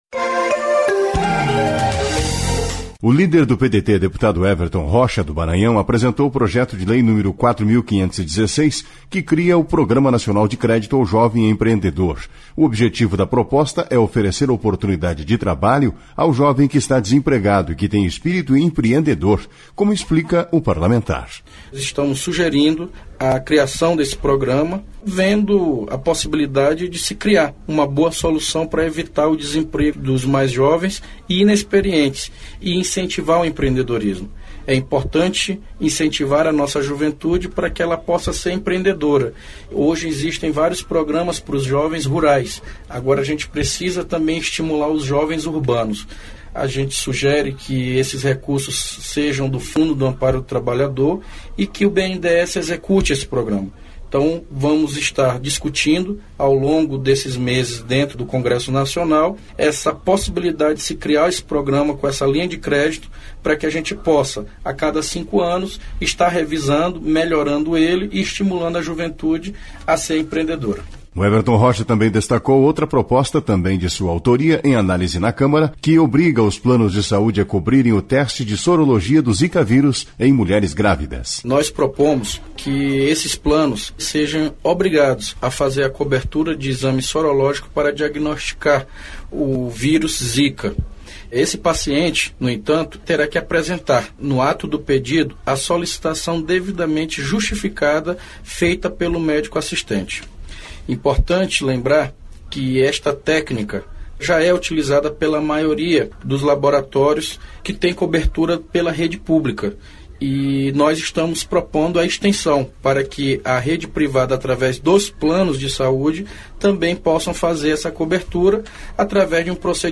Weverton Rocha e Flávia Morais falam de projetos em análise na Câmara